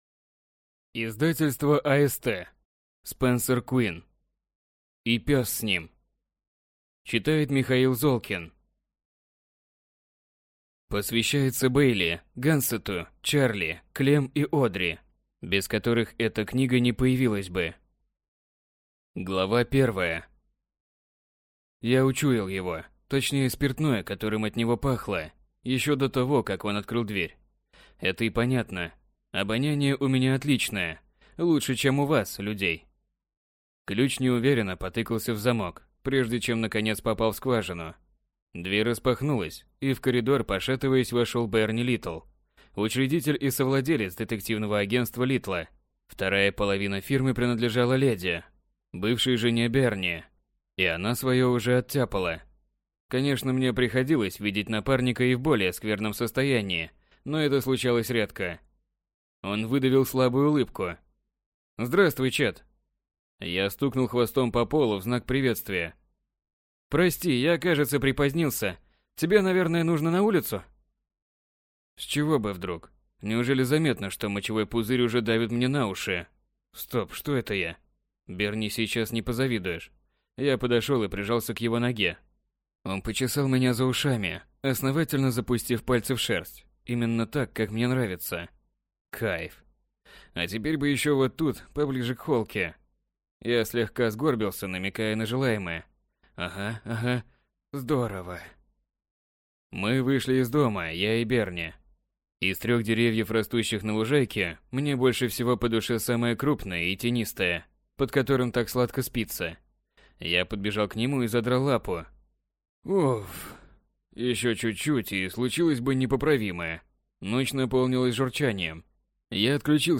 Аудиокнига И пес с ним | Библиотека аудиокниг